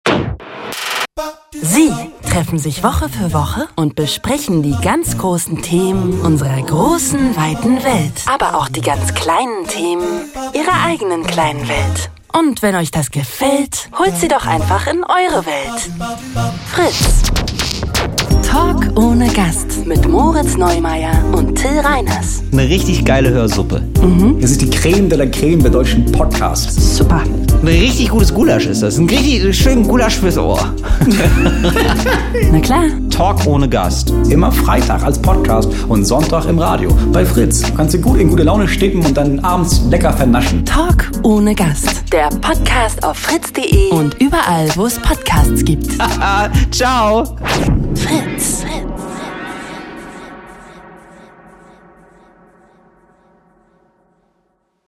Talk ohne Gast | Trailer
Was tun, wenn in einer Radiotalkshow ein prominenter Gast nach dem anderen absagt? Dann müssen Till Reiners und Moritz Neumeier eben allein das besprechen, was sie eigentlich ihren Gast gefragt hätten. Darüber schweifen sie gern auch zu den großen Themen unserer Zeit ab oder lassen einfach mal die Woche Revue passieren.